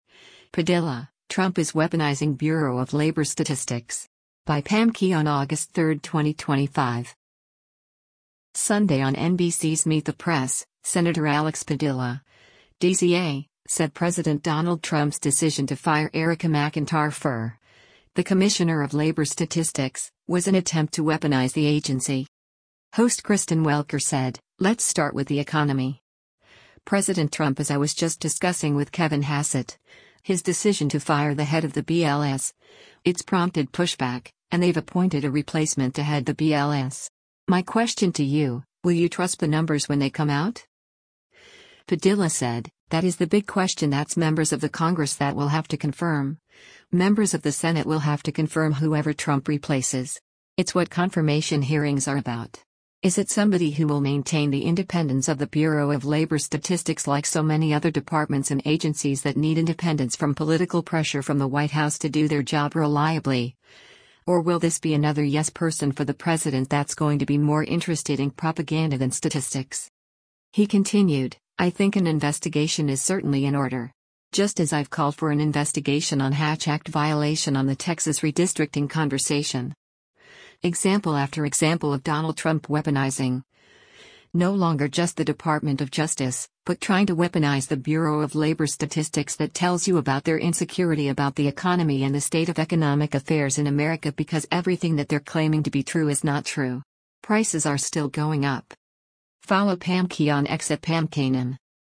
Sunday on NBC’s “Meet the Press,” Sen. Alex Padilla (D-CA) said President Donald Trump’s decision to fire Erika McEntarfer, the commissioner of labor statistics, was an attempt to weaponize the agency.